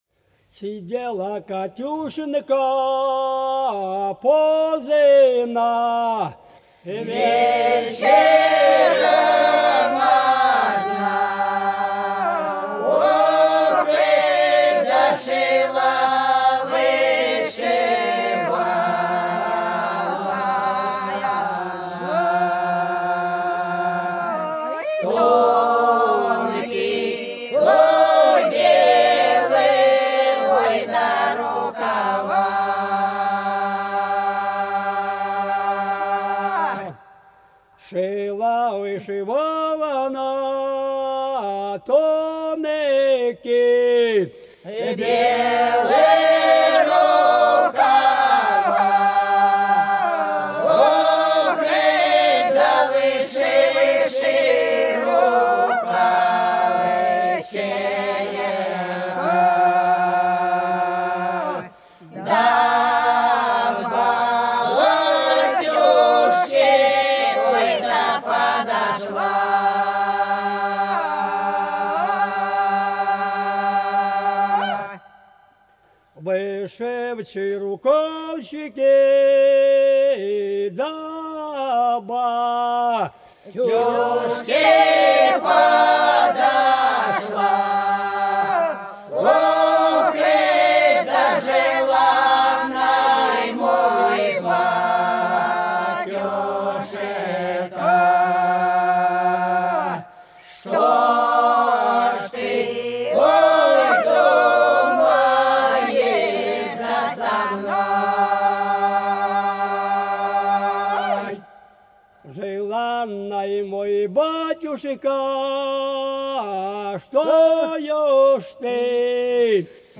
По-над садом, садом дорожка лежала Сидела Катюша поздно вечером одна - протяжная (с.Фощеватово, Белгородская область)